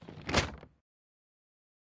Tag: 运动